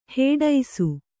♪ hēḍaisu